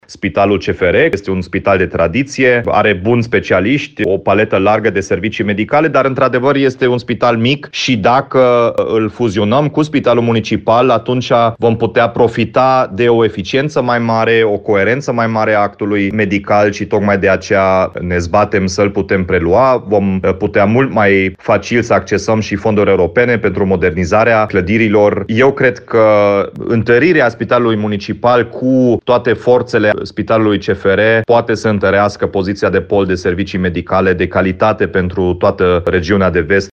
Primarul Dominic Fritz, care a primit distincția, în numele municipalității, spune că este un imbold în direcția promovării puternice a orașului, care anul acesta este Capitală Culturală Europeană.